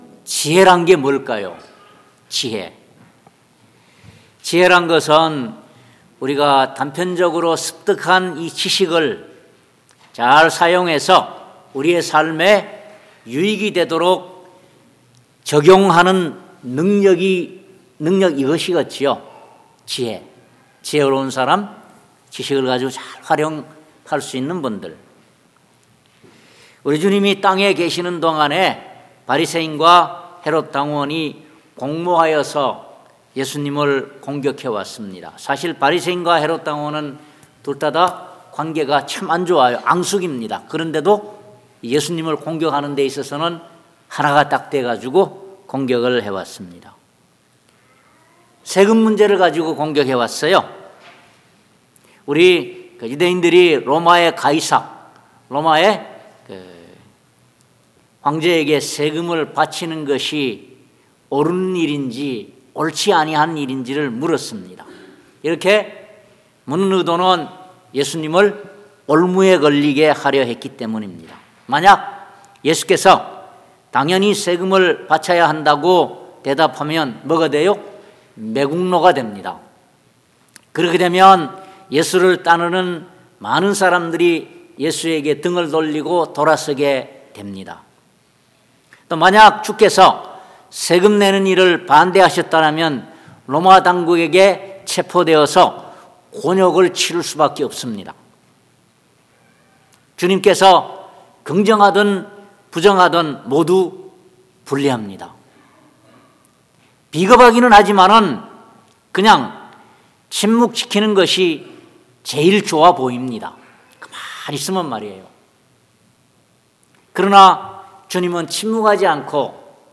고린도전서 2:1-12 Service Type: 주일예배 첫째로 오늘 본문 말씀을 통하여 우리 하나님께서 우리에게 우리의 신앙의 핵심이라고 할까 아니면 신앙의 토대라 할까 그것은 바로 십자가에 못 박히신 예수 그리스도가 되어야 한다라고 말씀하고 있습니다. 성경이 우리에게 제시하는 것은 인류의 구원에 대한 길입니다.